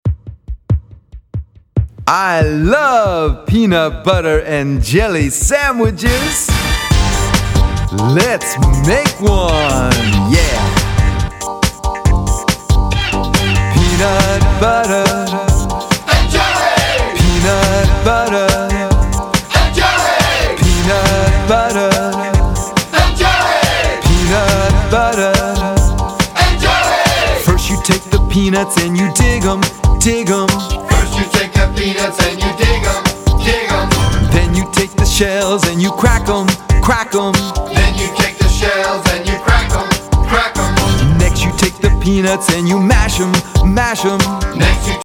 Hip-Hop and Pop Beats to Help Your Children Read